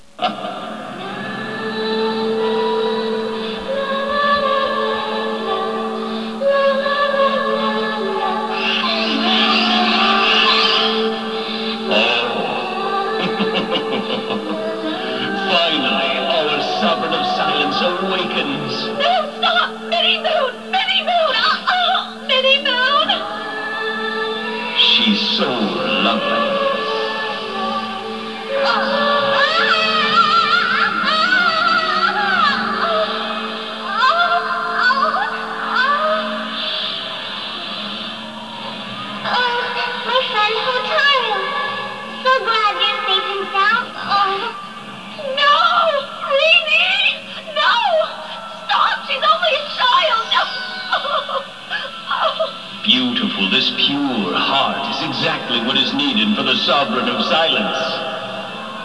Its soo eerie sounding and spooky, but very beautiful.